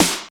46.07 SNR.wav